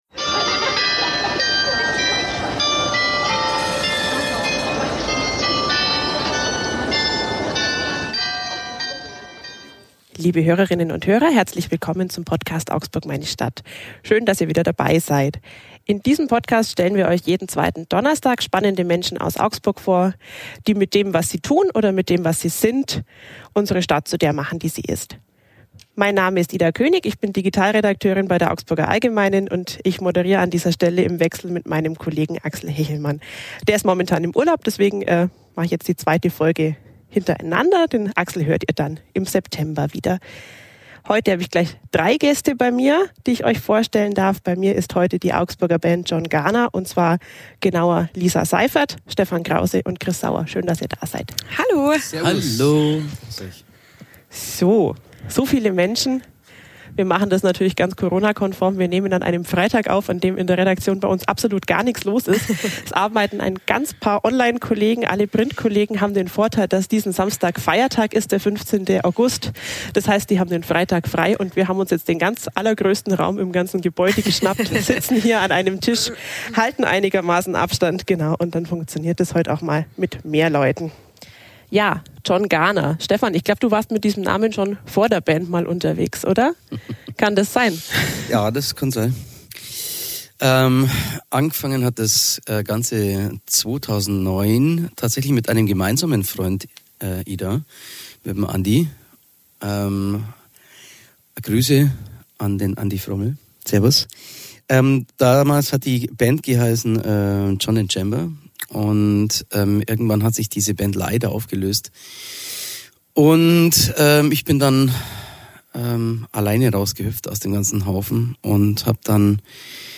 Einen Song daraus gibt es am Ende dieser Podcastfolge zu hören.